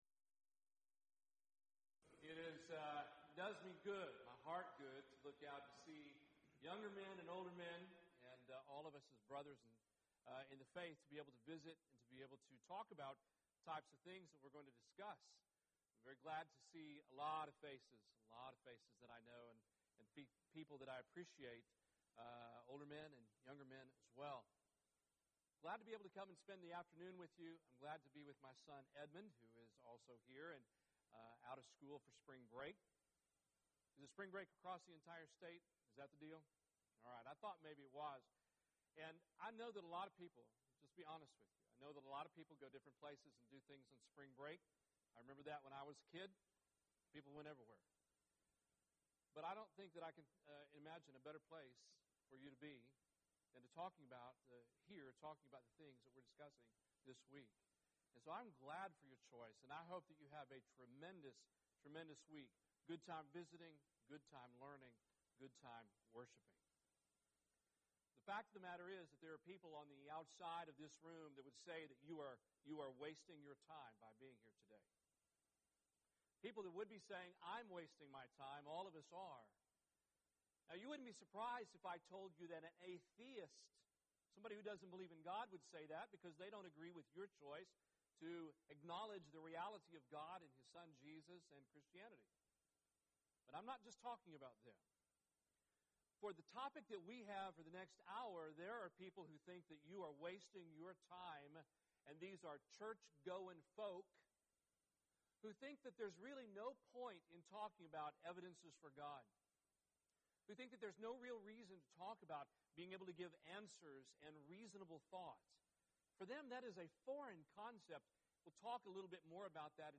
Event: 1st Annual Young Men's Development Conference
lecture